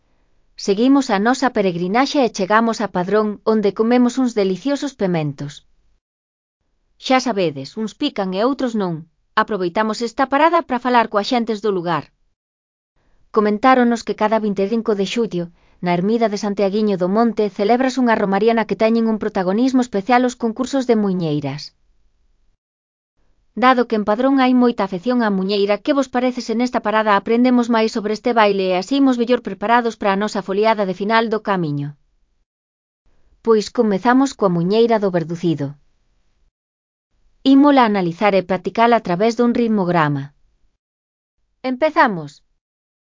3.3. Coñecemos a muiñeira